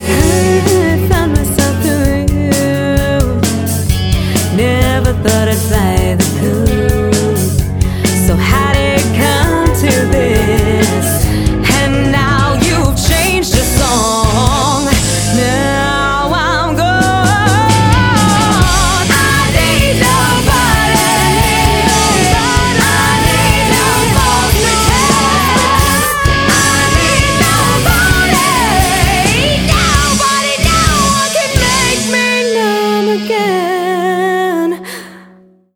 Bluesy Rock/Aggressive